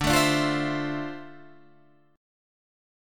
D Minor 9th